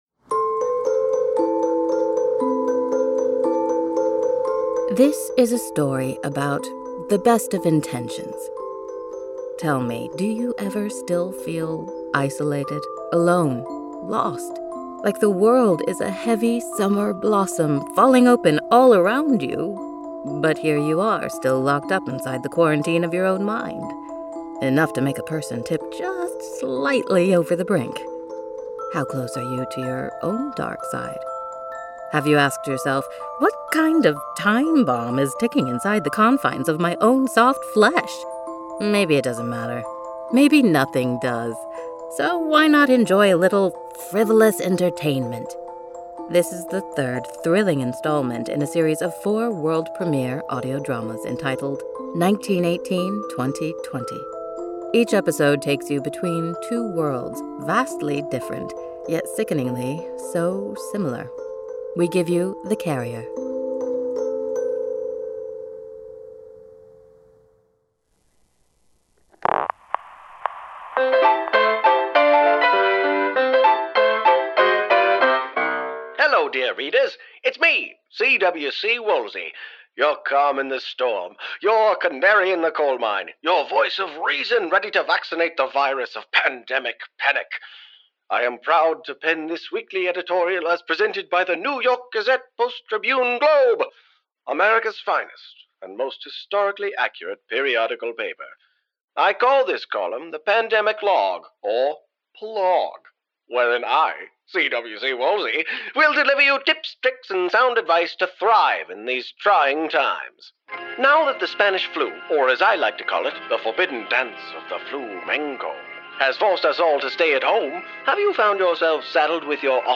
"The Carrier" is the third installment in our pandemic series, 1918/2020, original audio plays inspired by the past and present.